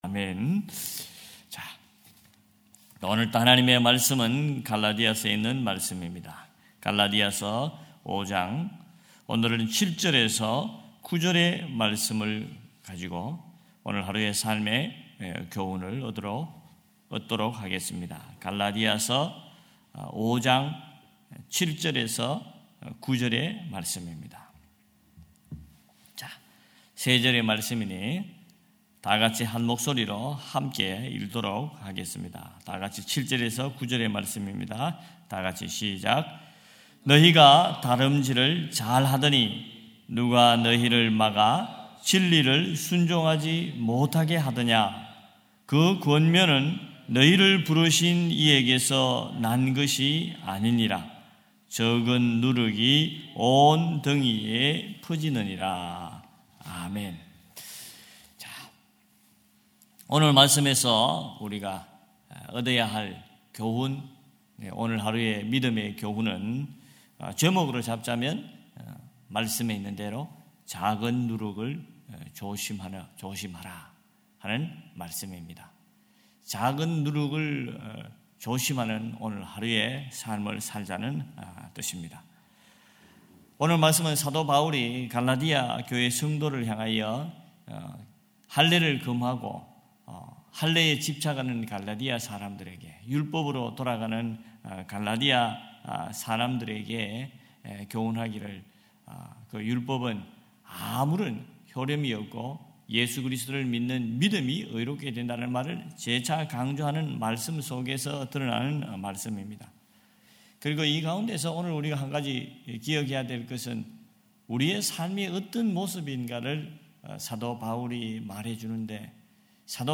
8.09.2025 새벽예배 갈라디아서 5장 7-9절